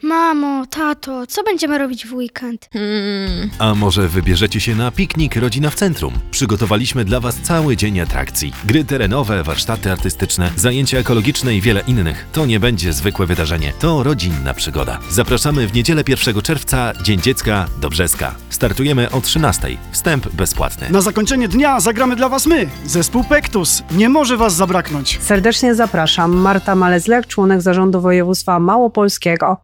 Nasz spot dźwiękowy: